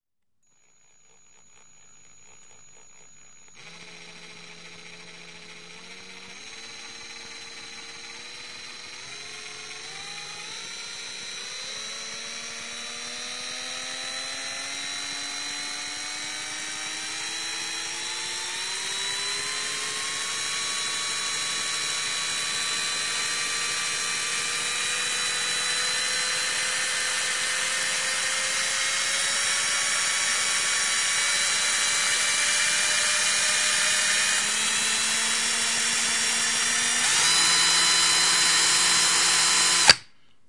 无绳拧线器。慢慢加速，然后停止。